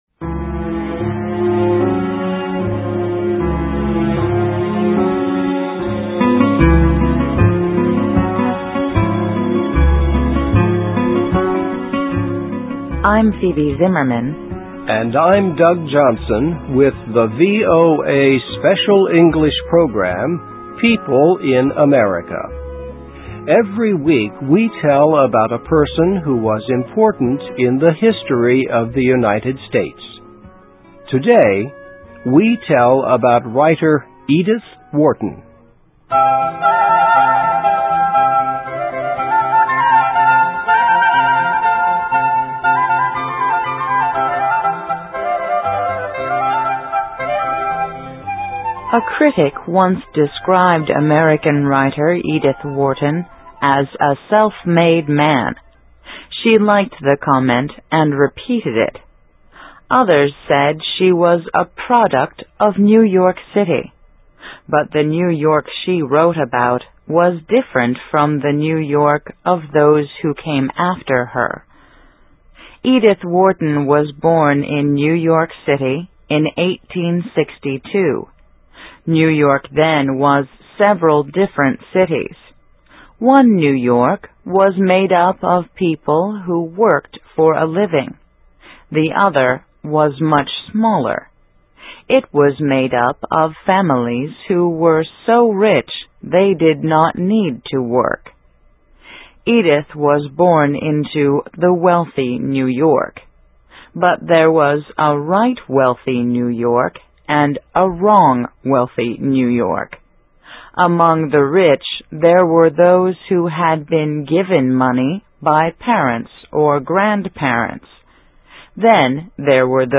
Biography - Edith Wharton, 1862-1937: Wrote Novels of the Young and Innocent in a Dishonest World (VOA Special English 2007-06-15)
Listen and Read Along - Text with Audio - For ESL Students - For Learning English